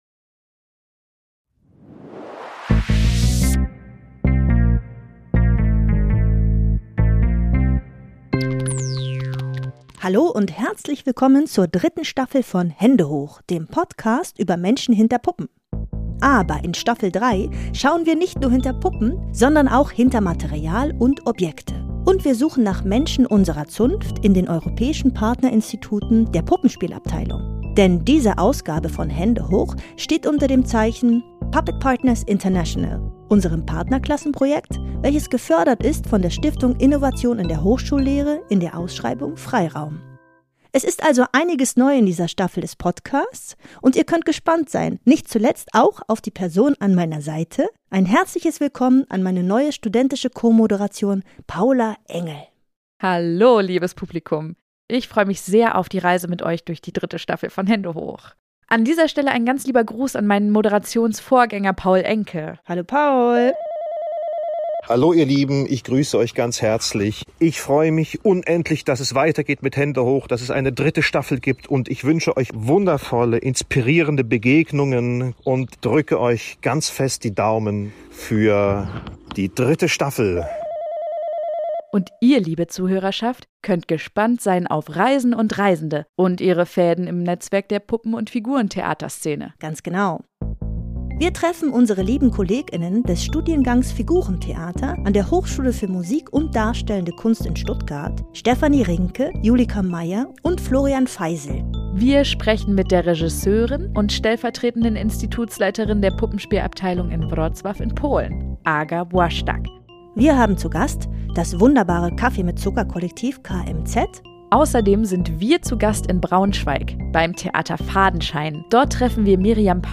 HÄNDE HOCH! Und Vorhang auf für Staffel Drei! (Teaser)